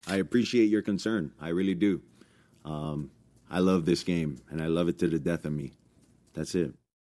” I appreciate your concern,” Tagovailoa said in a press conference. “I really do.”